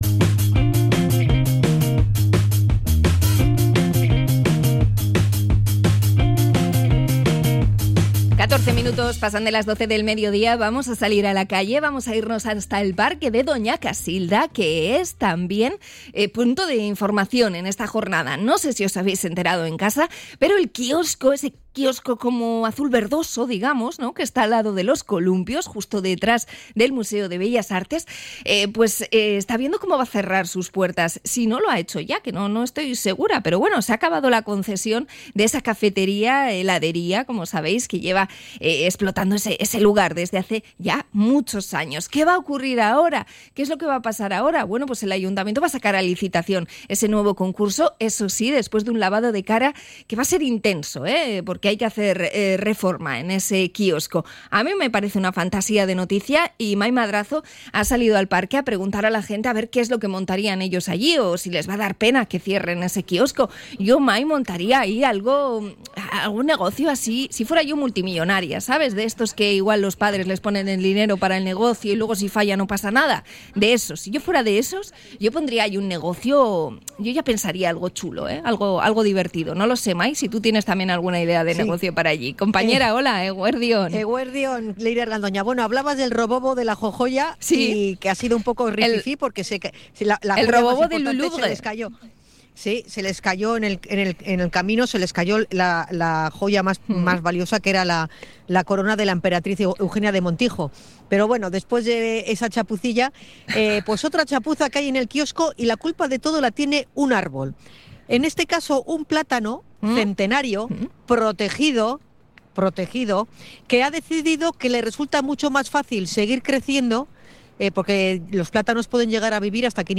Hablamos con los concesionarios y paseantes del parque de Doña Casilda
ENCUESTA-KIOSKO-DONA-CASILDA.mp3